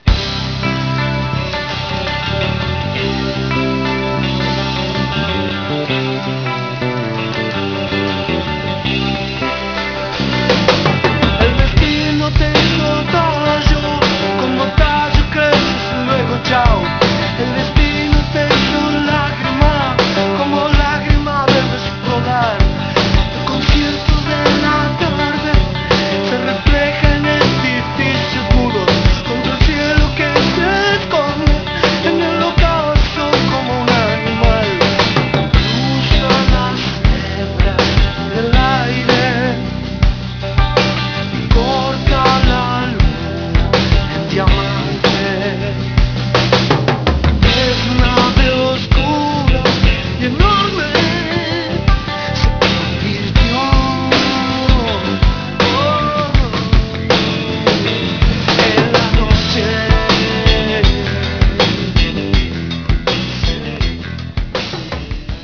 Guitarras y Voces